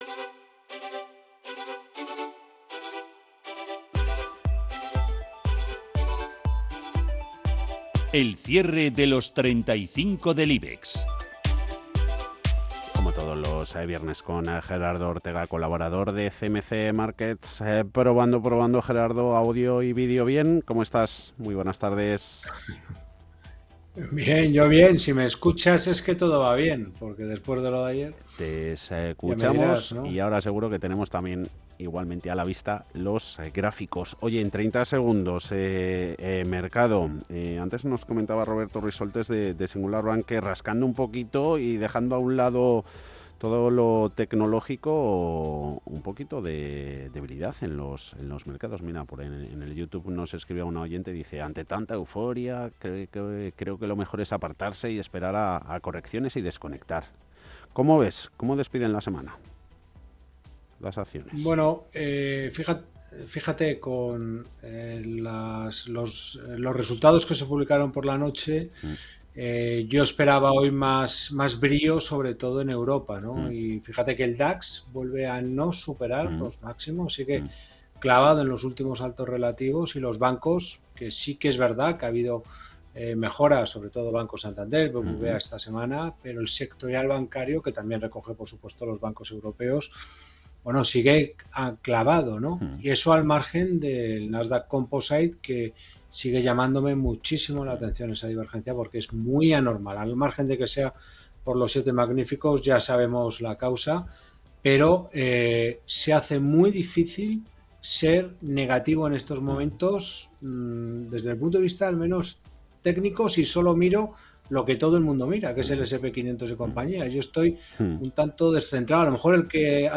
El audio que más abajo adjuntamos se corresponde con nuestra intervención semanal en Radio Intereconomía (95.1 FM Madrid) . Y es qué cada viernes, al cierre de la sesión, conectamos con la radio para, en riguroso directo desde las 17:40h , repasar una selección de los 35 valores que componen nuestro índice.